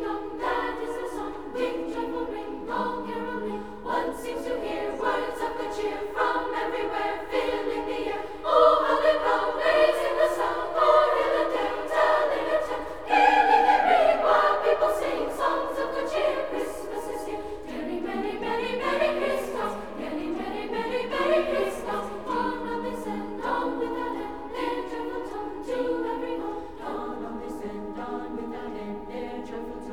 • Soundtrack